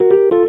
neppiano3.wav